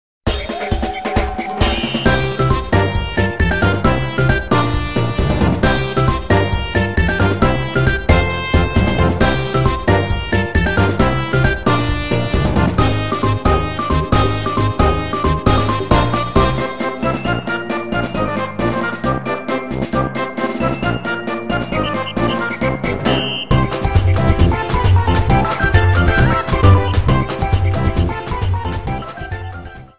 Main theme
applied fade-out You cannot overwrite this file.